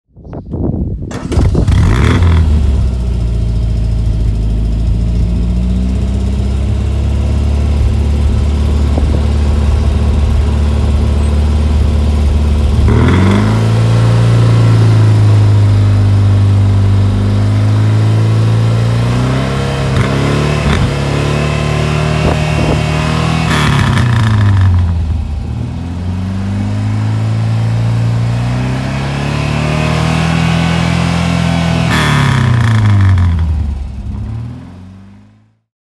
Rover 2000 TC (1967) - Starten und Leerlauf